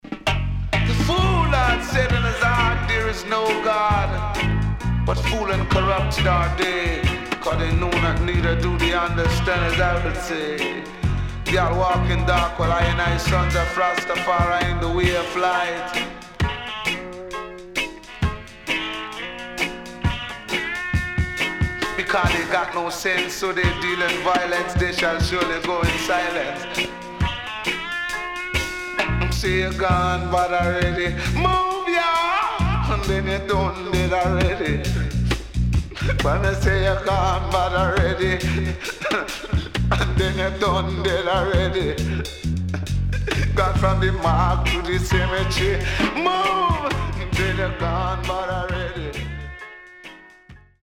HOME > LP [VINTAGE]  >  70’s DEEJAY  >  RECOMMEND 70's